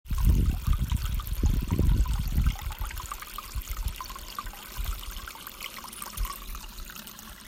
Sound 3 = Babbling brook